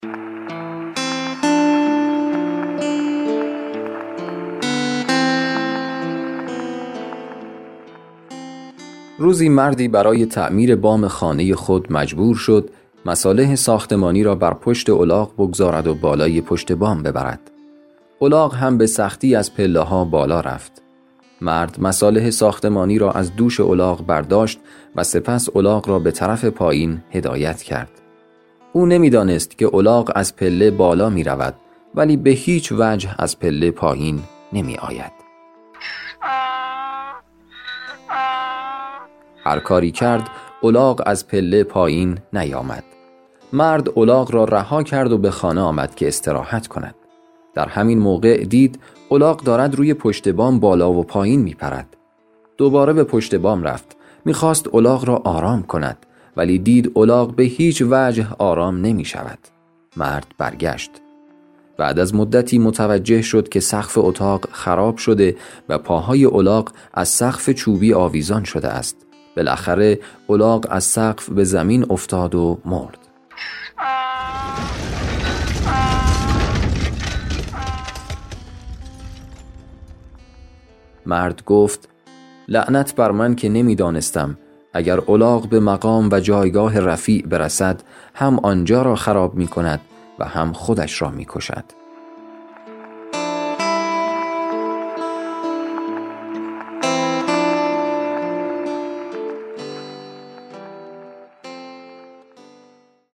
داستان صوتی کوتاه - الاغ را بالای پشت بام برد. او نمی دانست که الاغ از پله بالا می رود ولی به هیچ وجه از پله پایین نمی آید